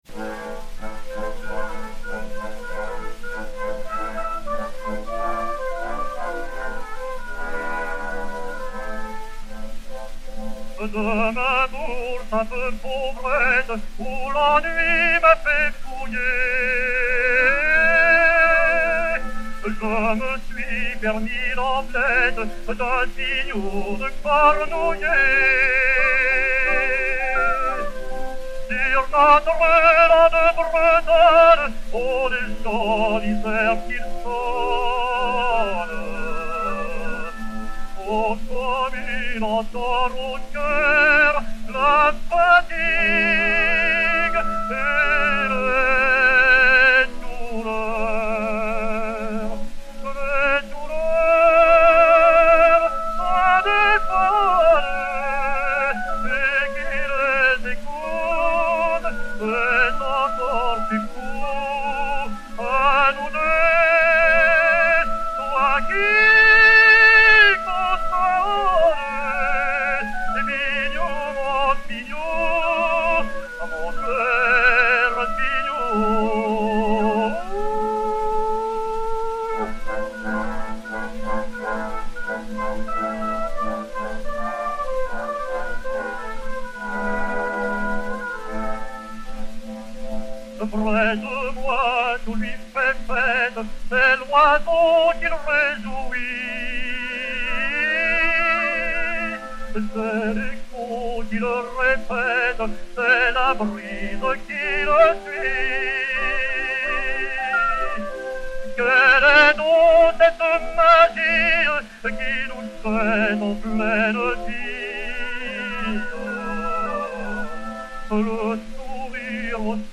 chanson bretonne (par.